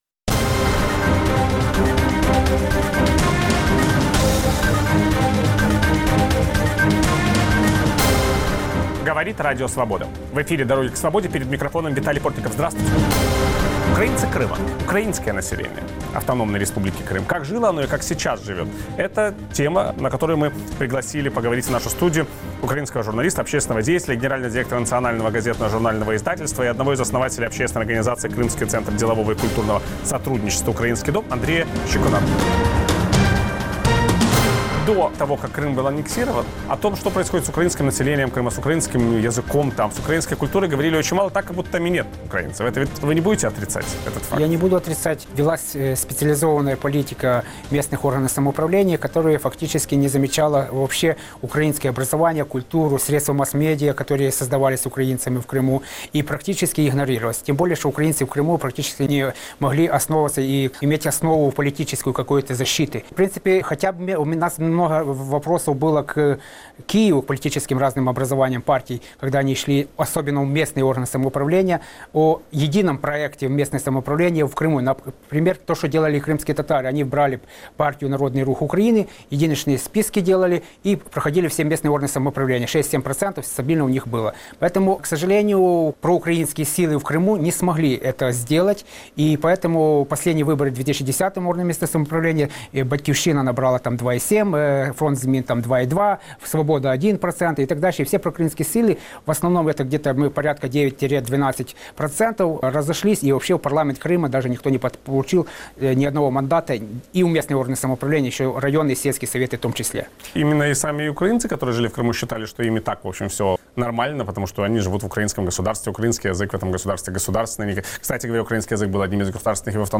О жизни украинцев Крыма известно куда меньше, чем о крымских татарах или русских. Какой была украинская жизнь Крыма до аннексии и что происходит сегодня? Собеседник Виталия Портникова